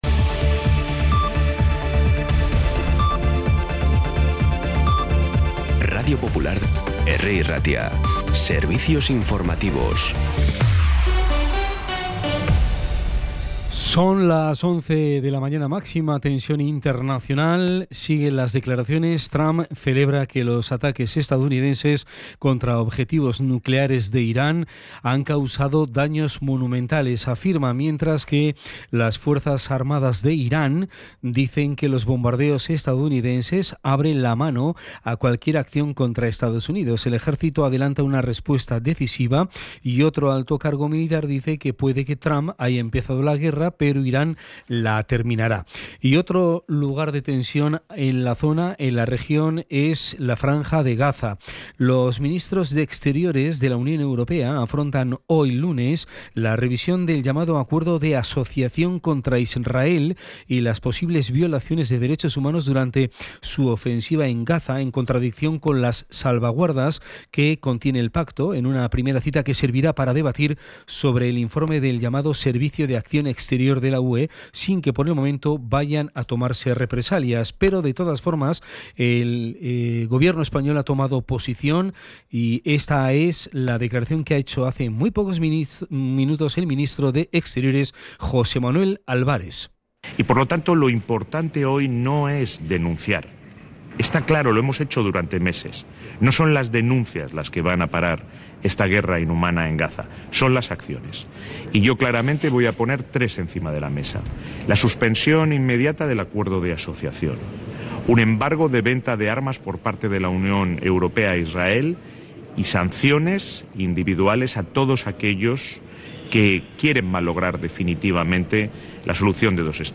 Información y actualidad desde las 11 h de la mañana